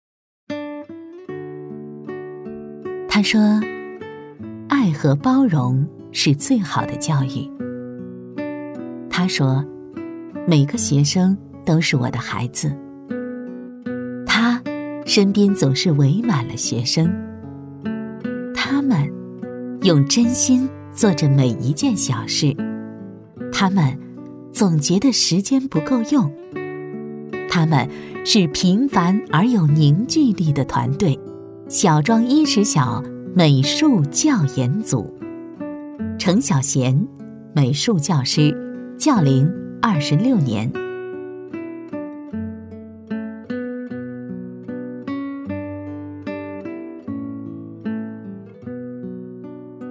配音风格： 感情丰富，自然活力
【旁白】晓庄